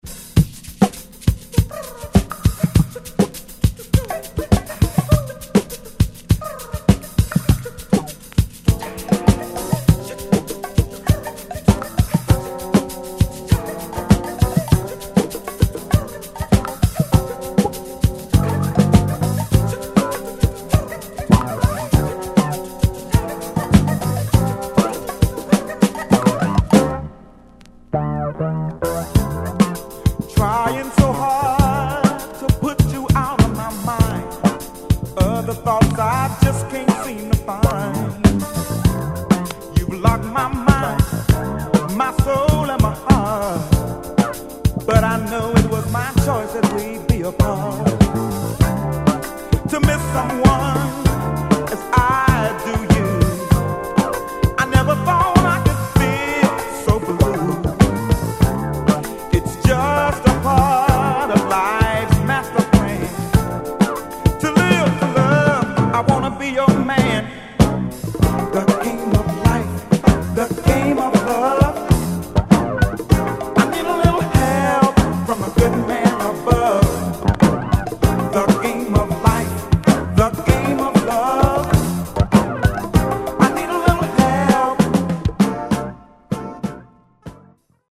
ホーム SOUL / FUNK LP C